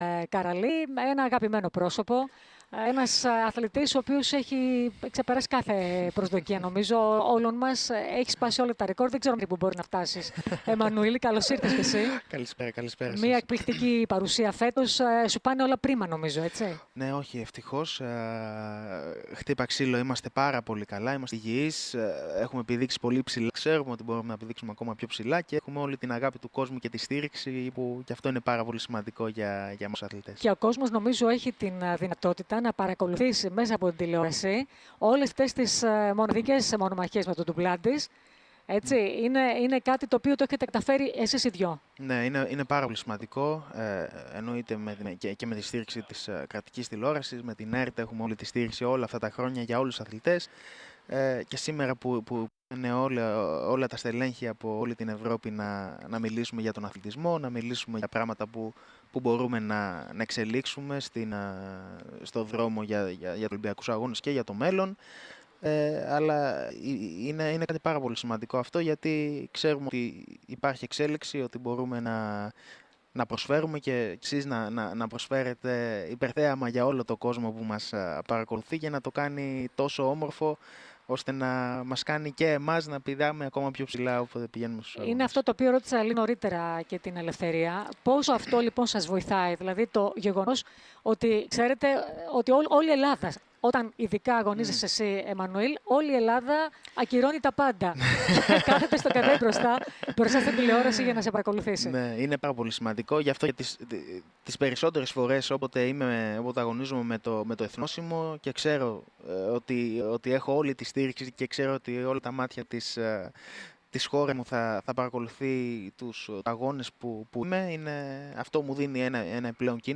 Η ΕΡΑ ΣΠΟΡ ήταν στη 38η Αθλητική Συνέλευση της EBU μαζί με κορυφαίους αθλητές.